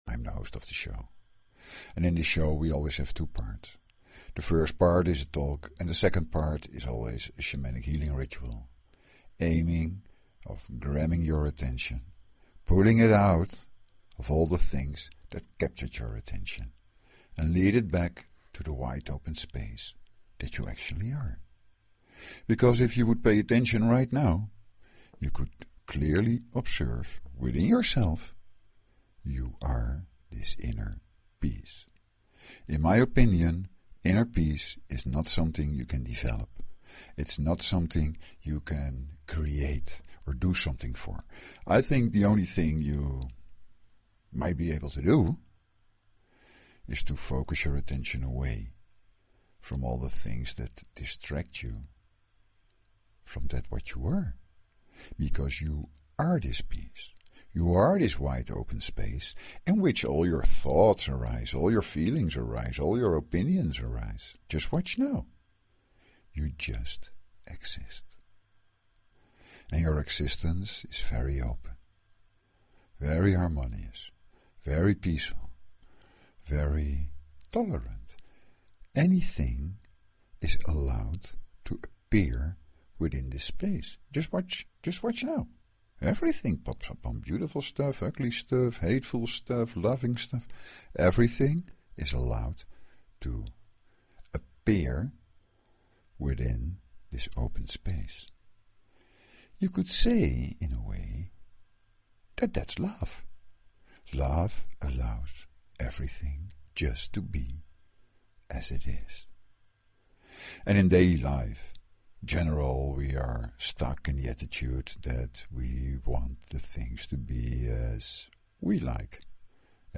Talk Show Episode, Audio Podcast, Shuem_Soul_Experience and Courtesy of BBS Radio on , show guests , about , categorized as
To make use of this meditation optimally it is best not to get disturbed, listen to it through headphones and lie or sit down in a comfortable position.